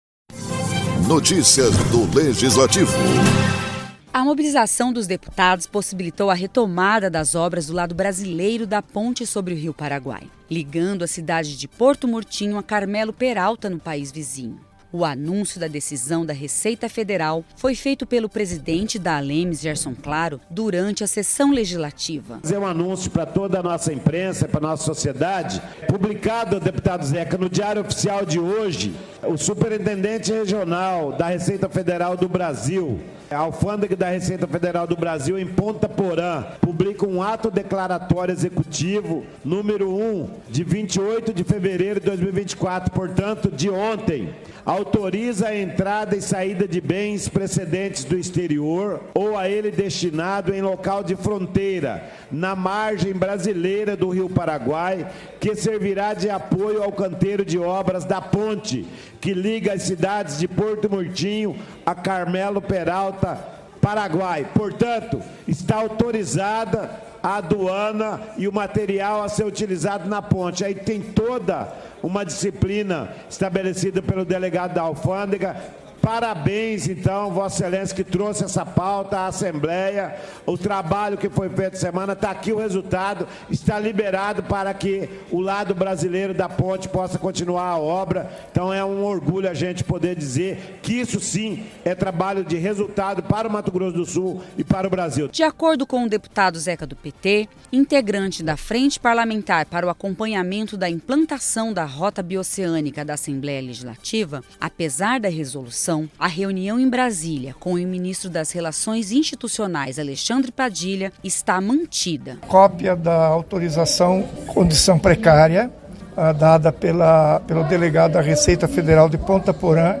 O anúncio da decisão da Receita Federal foi feita pelo presidente da ALEMS, Gerson Claro, durante a sessão legislativa.